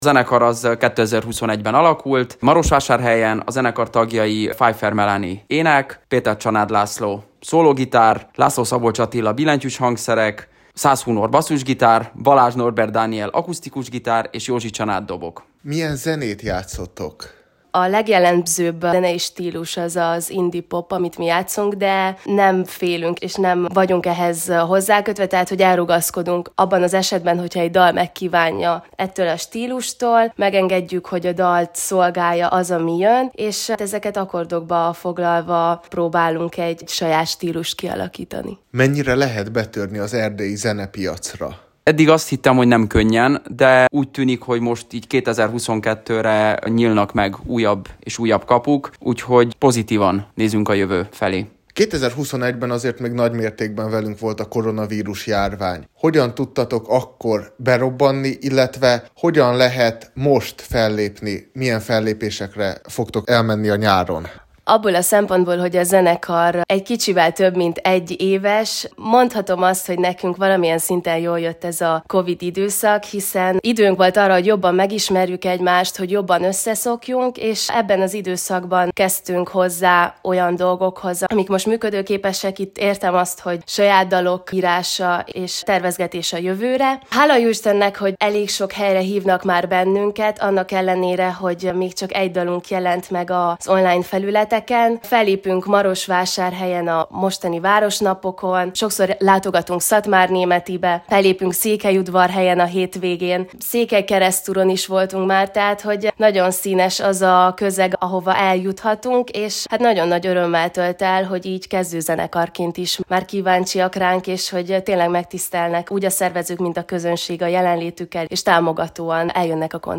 beszélgetett